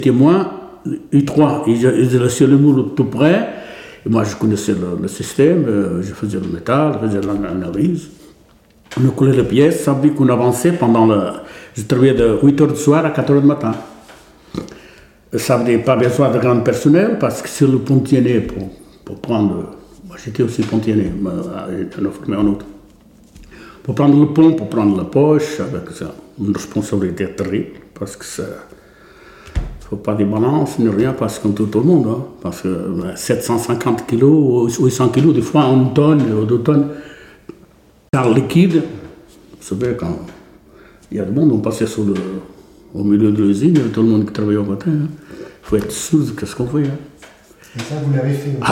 Figure 10 : Extrait vidéo du témoignage d’un fondeur.